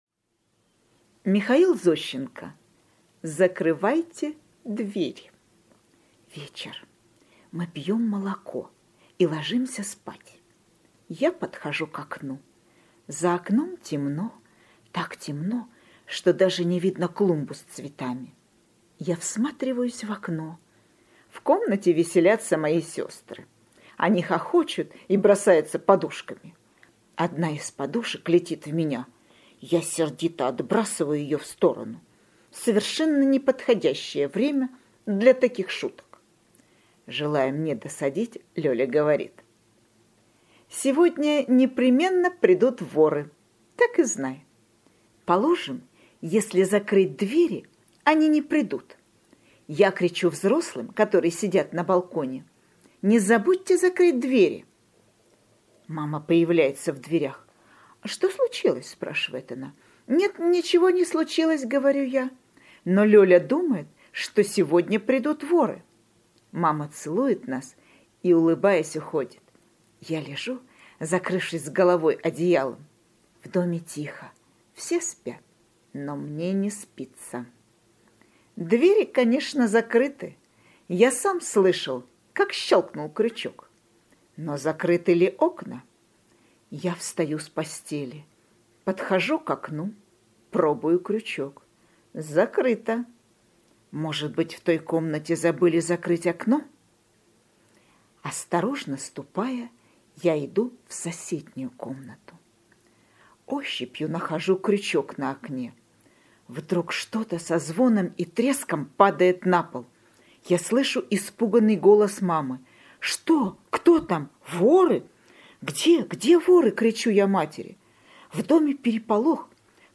Аудиорассказ «Закрывайте двери»